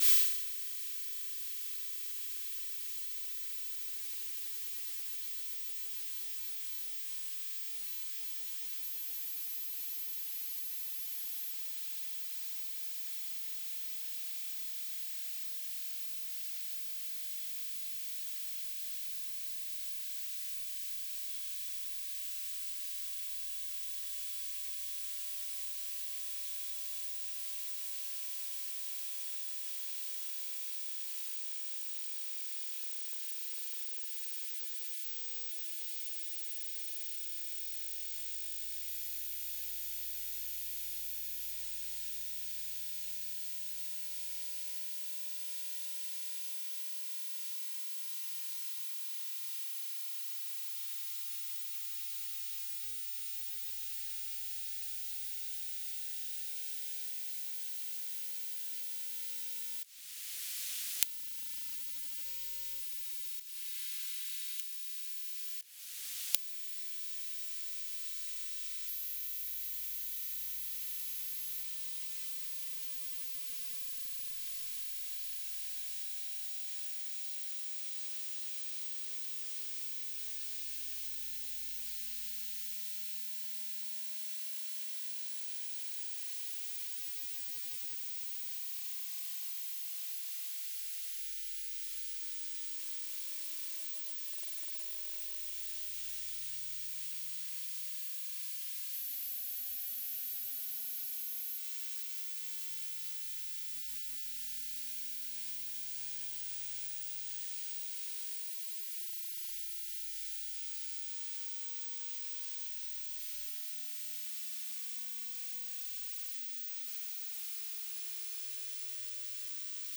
"transmitter_description": "BPSK1k2 AX.25 TLM",